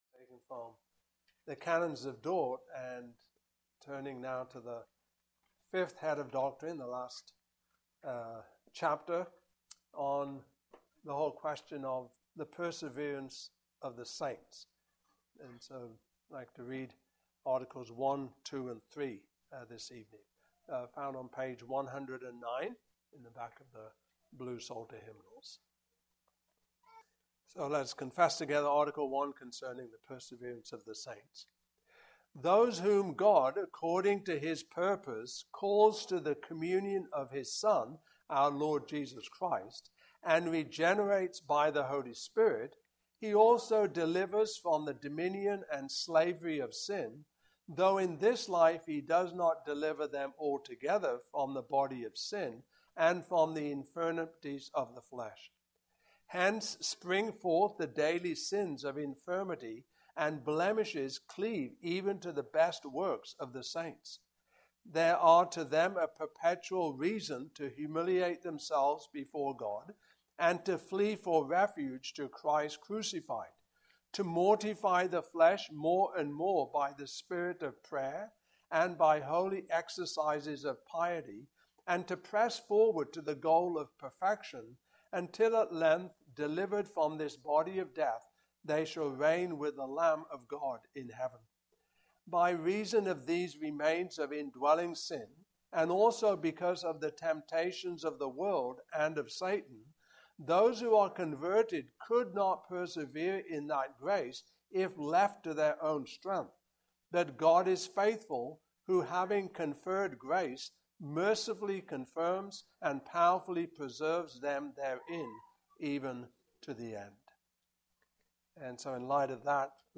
Series: Canons of Dordt Passage: Romans 8:12-39 Service Type: Evening Service Topics